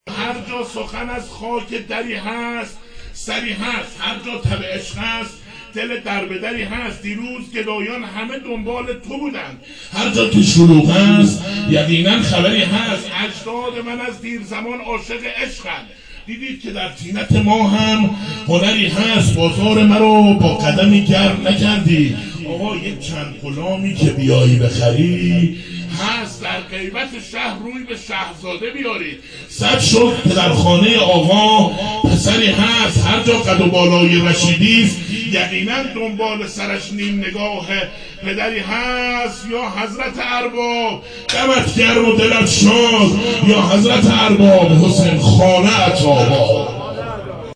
مدح حضرت علی اکبر علیه السلام